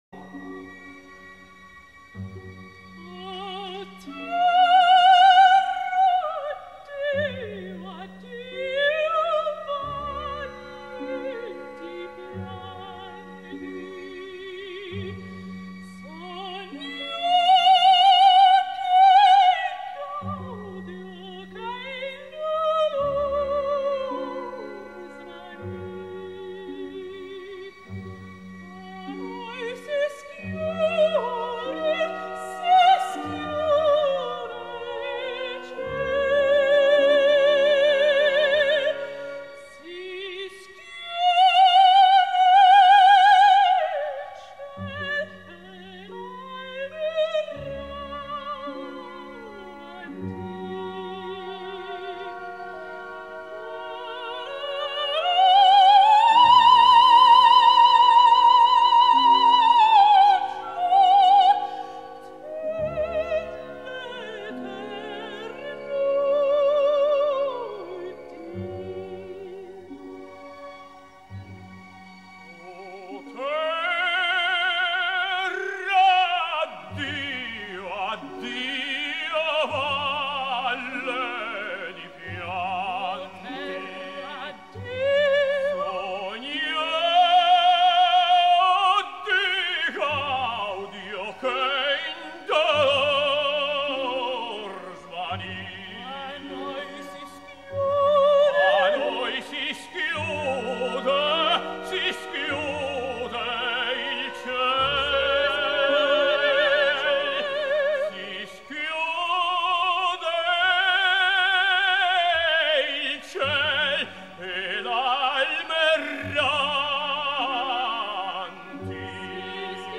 歌剧结尾的二重唱非常感人。